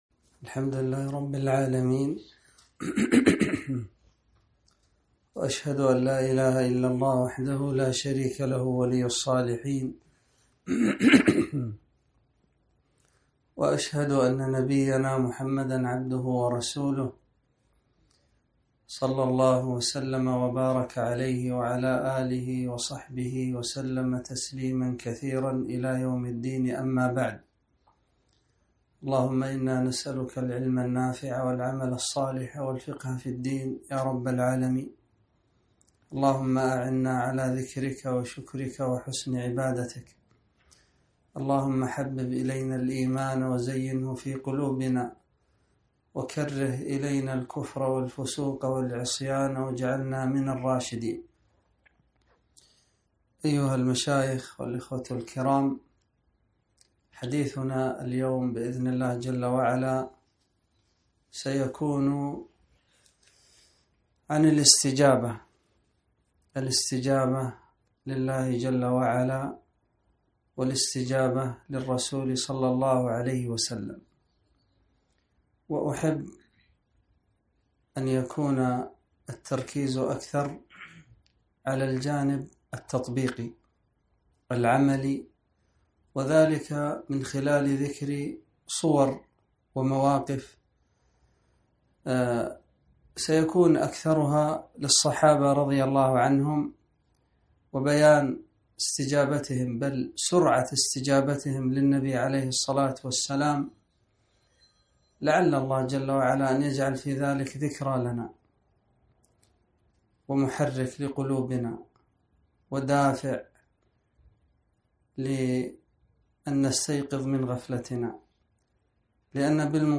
محاضرة - الاستجابة